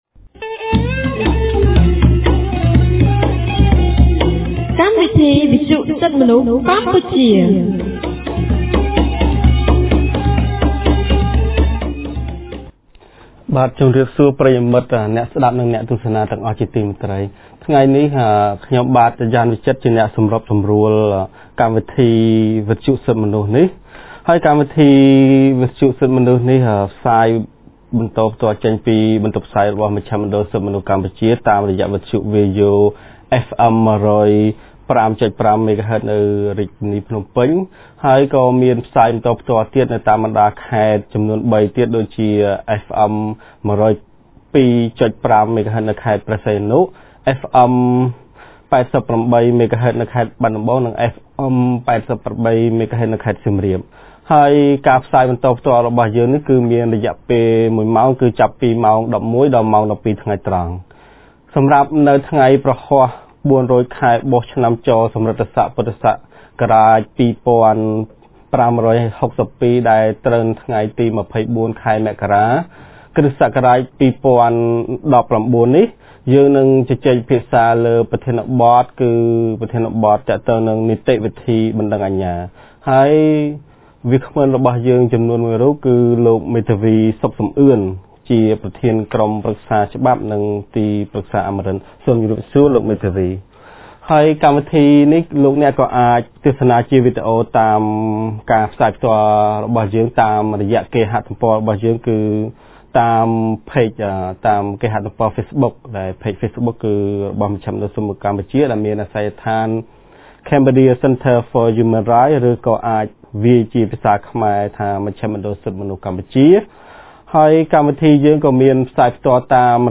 On 24 January 2019, CCHR’s Fair Trial Rights Project (FTRP) held a radio program with a topic on Procedure of Criminal Actions.